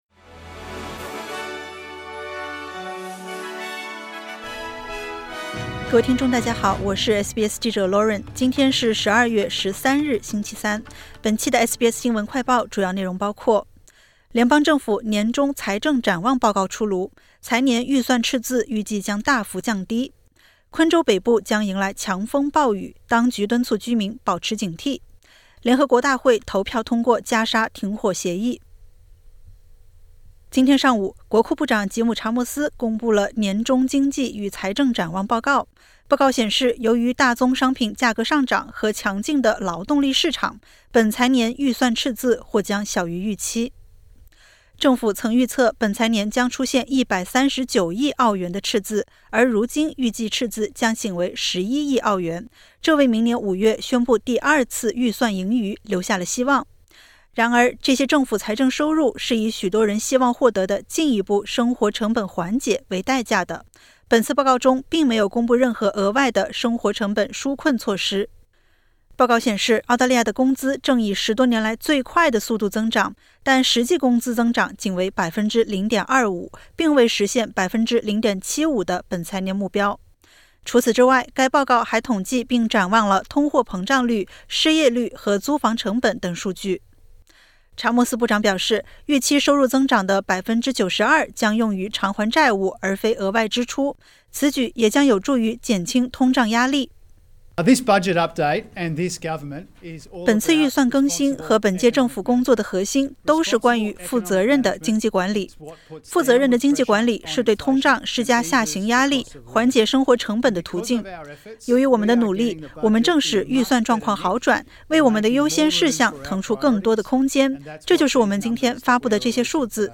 【SBS新闻快报】政府年中财政展望报告出炉 财年预算赤字预计大幅降低